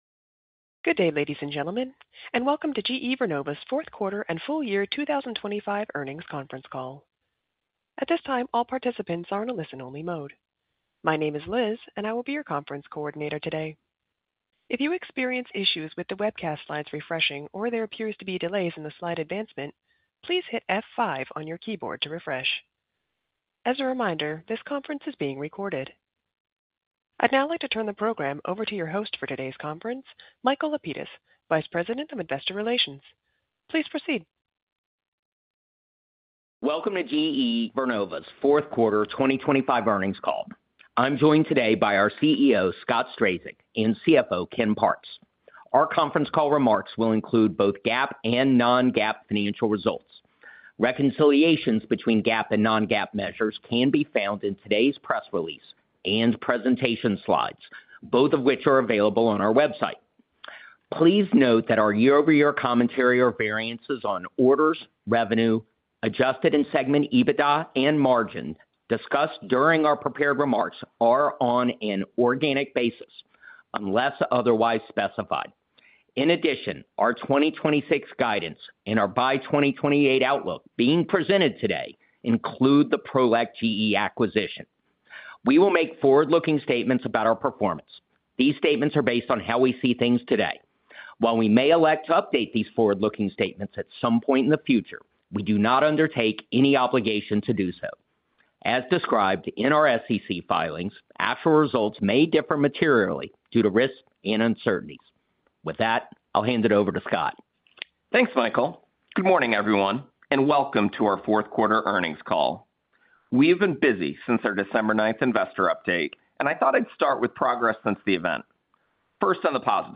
GE Vernova leadership team presented.